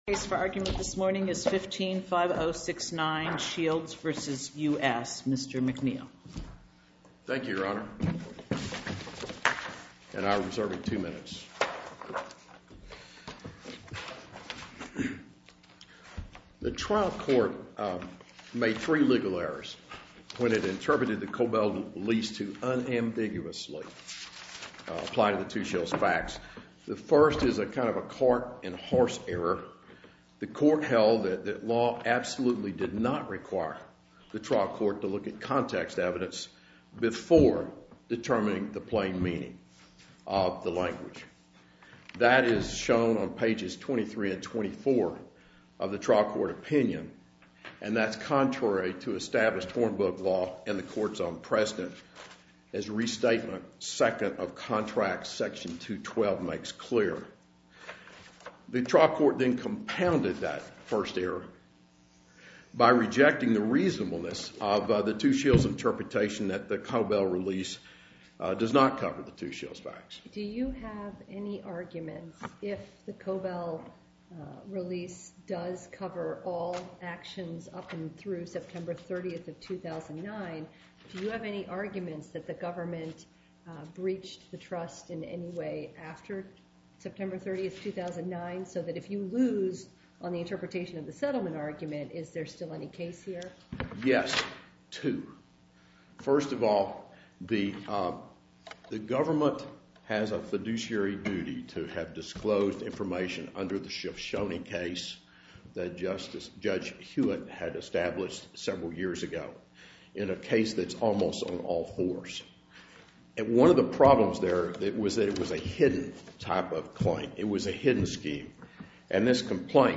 To listen to more oral argument recordings, follow this link: Listen To Oral Arguments.